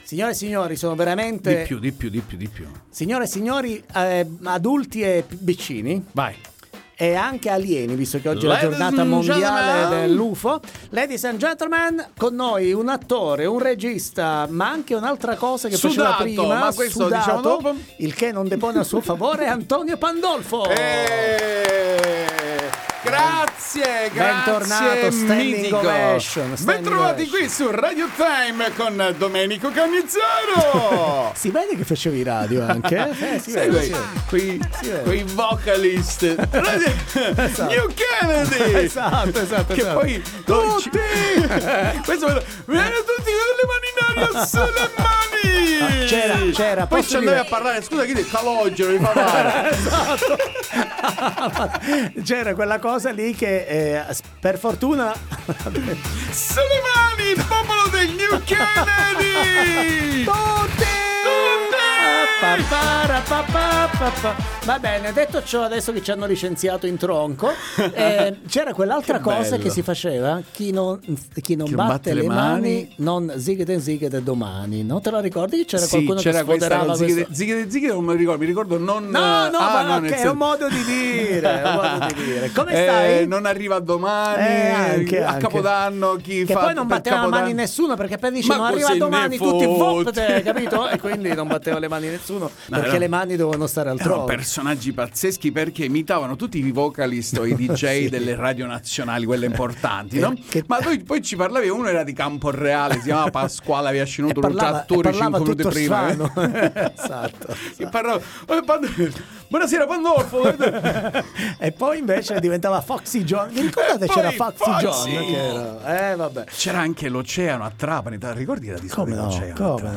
TANDEM Interviste